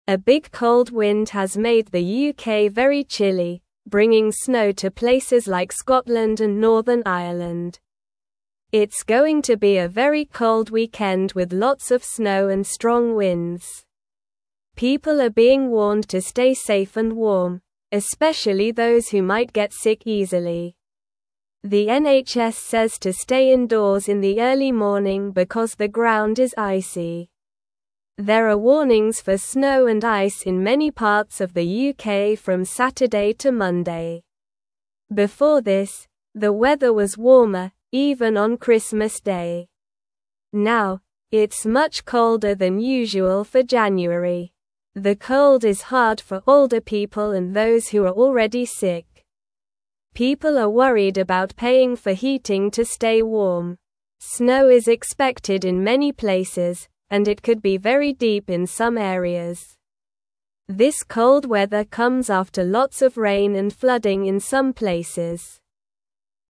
Slow
English-Newsroom-Beginner-SLOW-Reading-Big-Cold-Wind-Brings-Snow-to-the-UK.mp3